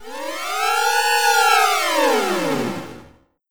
snd_ceroba_scream.wav